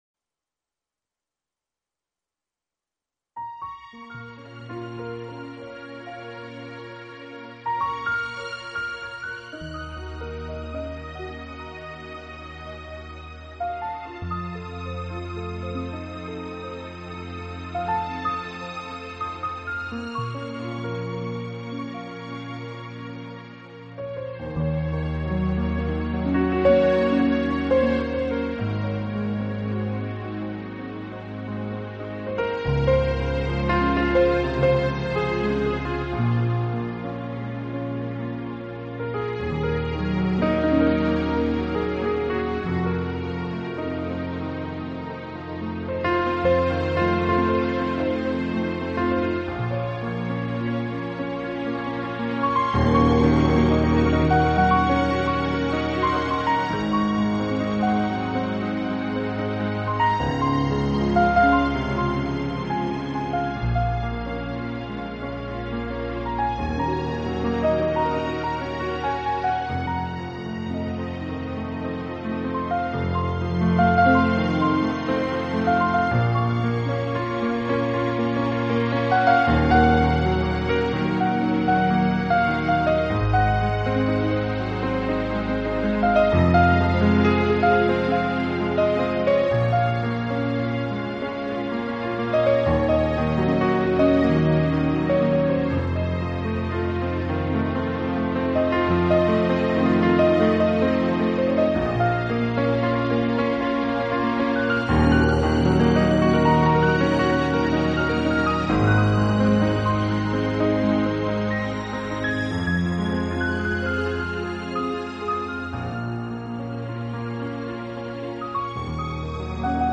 Genre : New Age